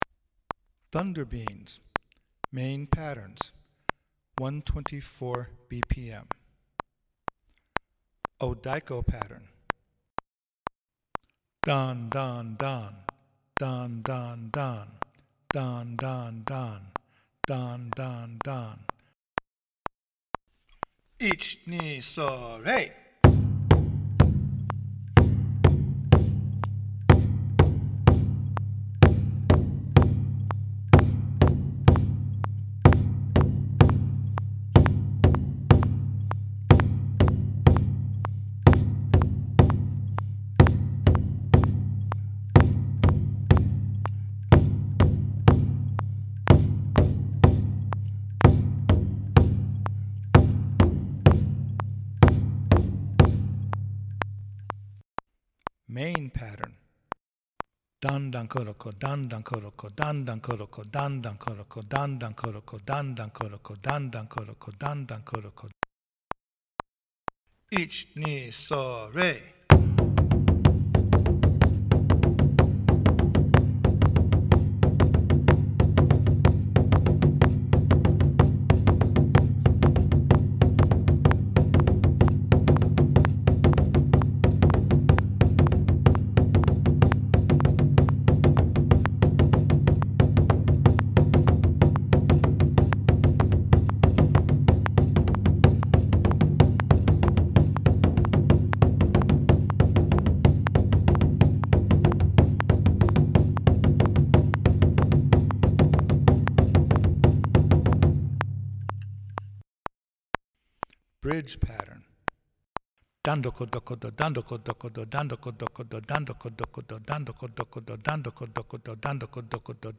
It is polyrhythmic and contains rhythmic subtiltes like interrruptions and triplets. It is also quite upbeat and energetic.
A recording of the Main Odiko & Chu patterns of Kaminari Tamashi at 124 BPM, separate, with kuchishoga lead-ins.